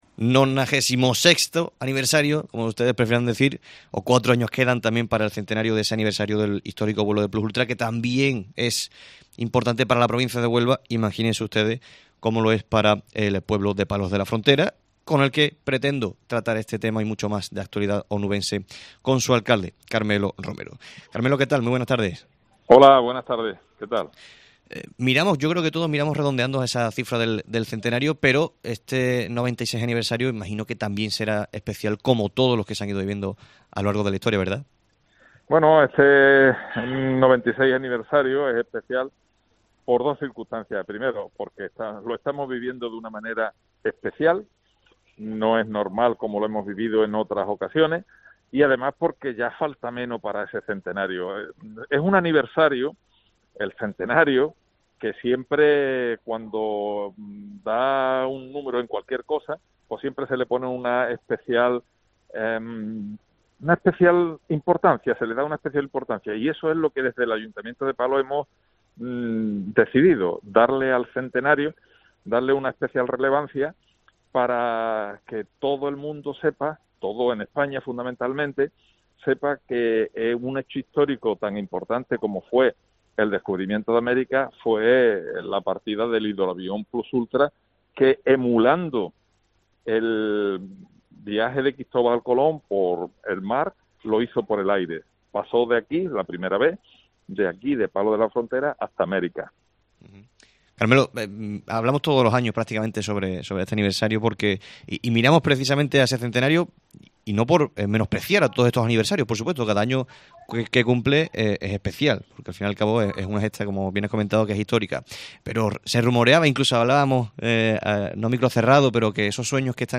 Carmelo Romero, alcalde de Palos de la Frontera